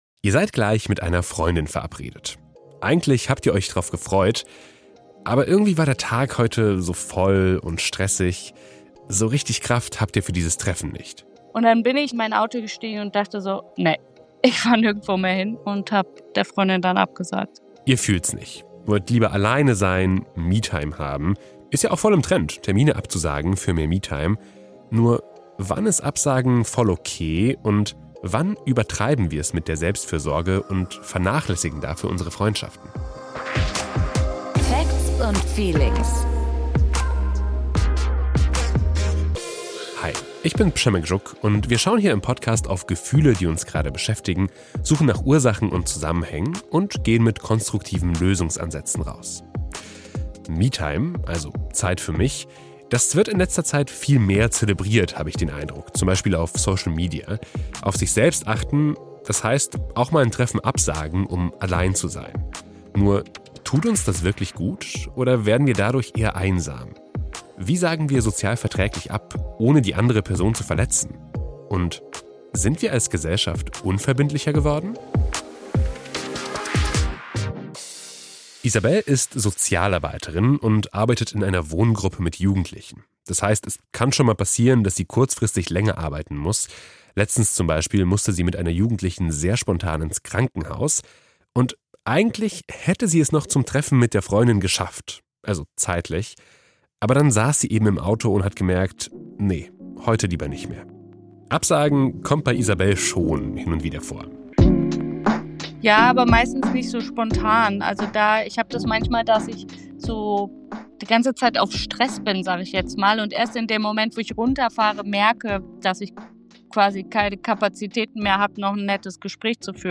Warum das in ihrer Generation akzeptierter ist als in älteren, erklären eine Soziologin und ein Generationenforscher.